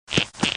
AV_side_step.ogg